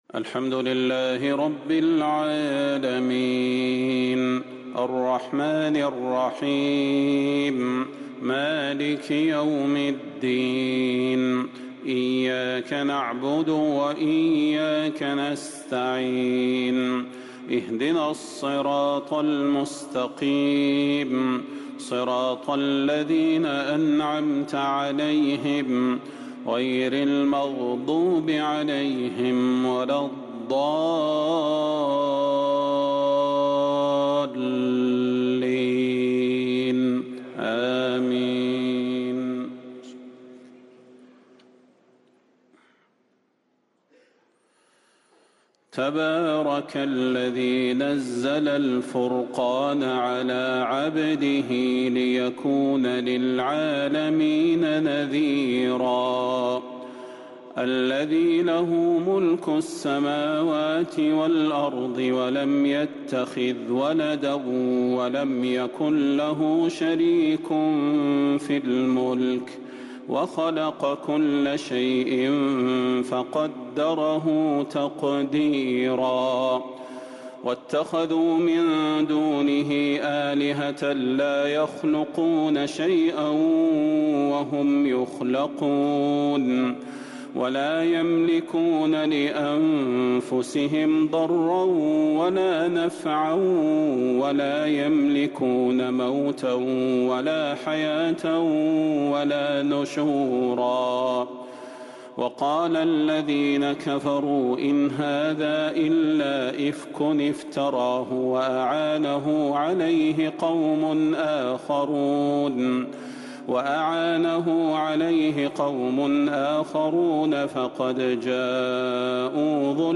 تهجد ليلة 22 رمضان 1444هـ سورة الفرقان كاملة | Tahajjud 22st night Ramadan1444H Surah Al-Furqan > تراويح الحرم النبوي عام 1444 🕌 > التراويح - تلاوات الحرمين